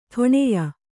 ♪ ṭhoṇeya